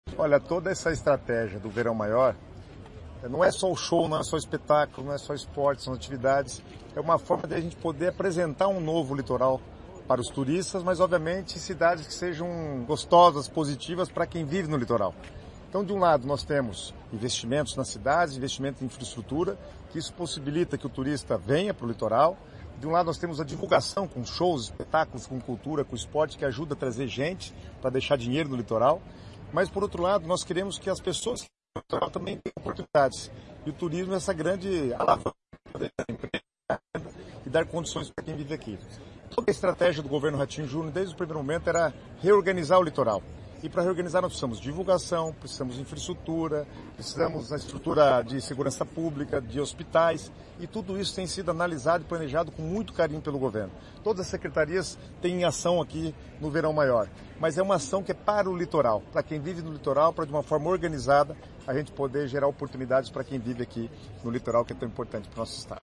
Sonora do secretário das Cidades, Guto Silva, sobre o primeiro fim de semana de shows do Verão Maior Paraná